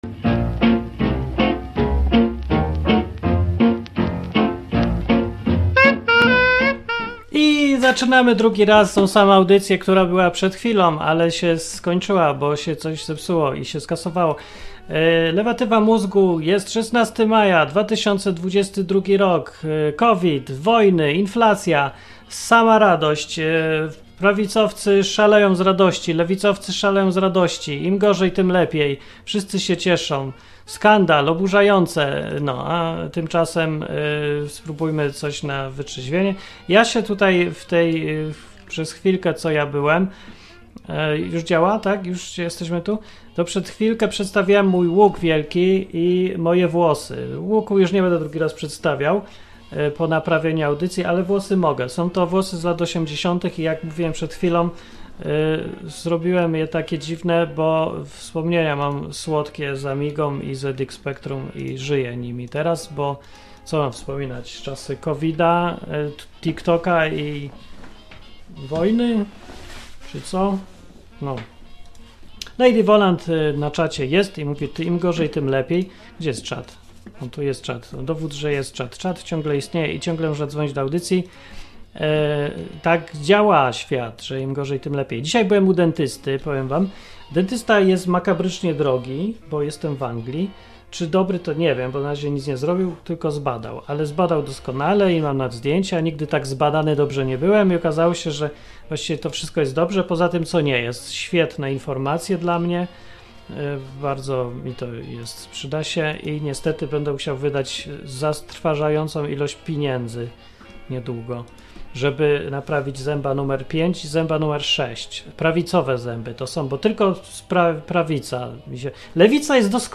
A na koniec wyrwał mi się monolog o różnicy między młodą Europą a starą Europą.
Program satyryczny, rozrywkowy i edukacyjny.